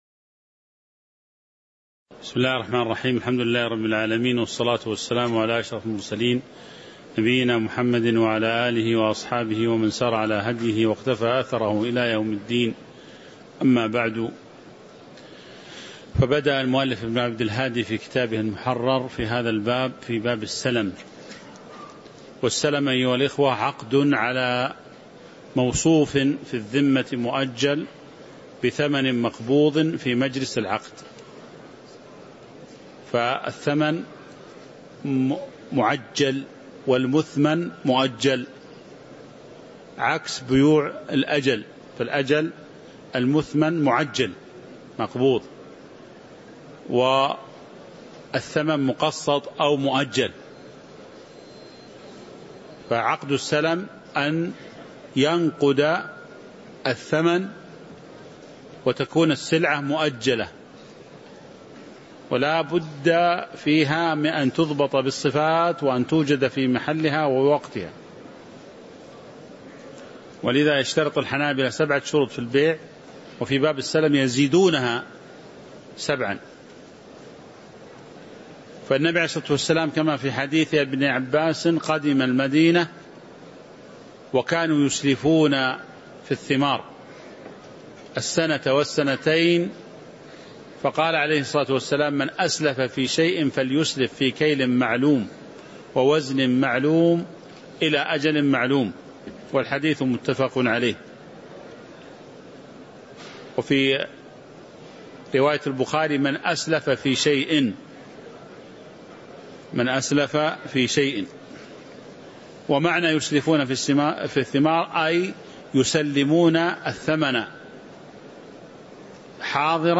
تاريخ النشر ٣ رجب ١٤٤٦ هـ المكان: المسجد النبوي الشيخ